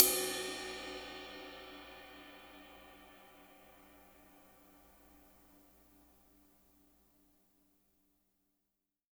-RIDE AC  -L.wav